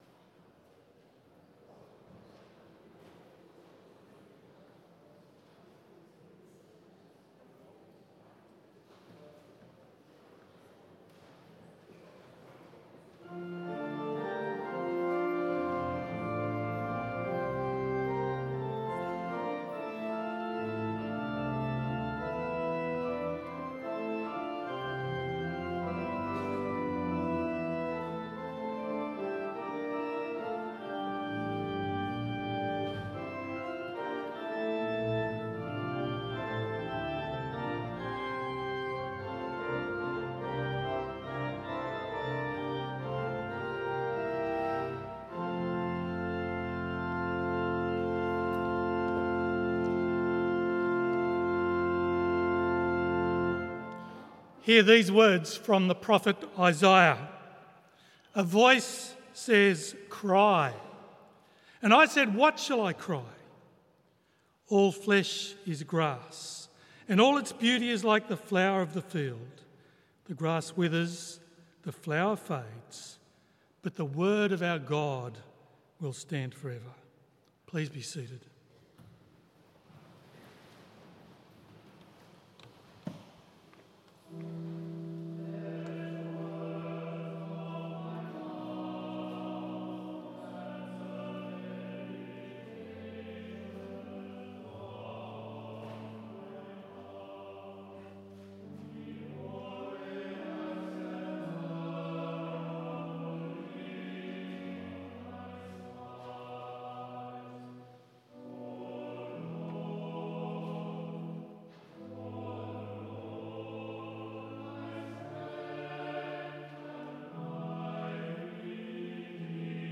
Full Service Audio